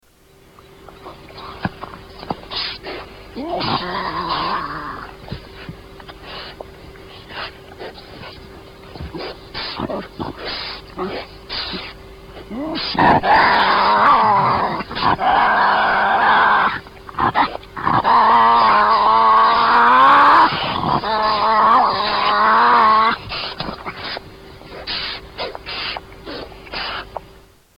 Tasmanian Devils fighting over food